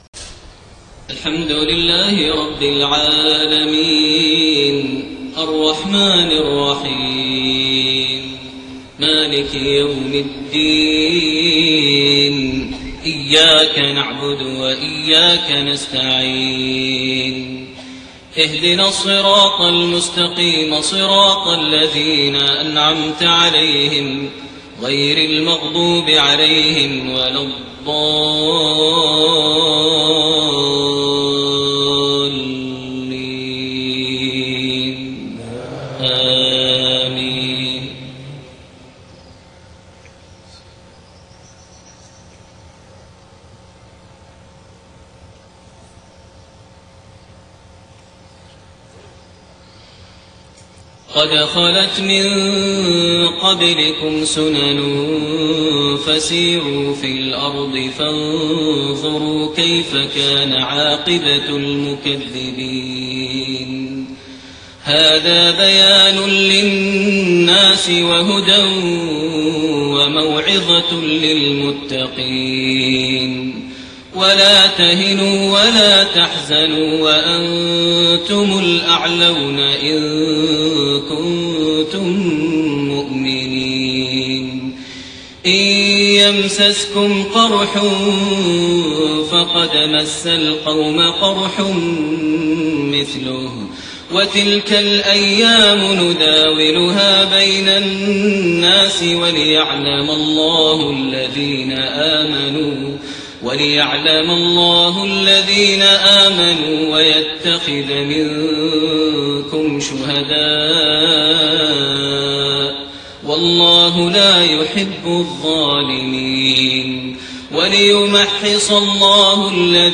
Maghrib prayer from Surat Aal-i-Imraan > 1430 H > Prayers - Maher Almuaiqly Recitations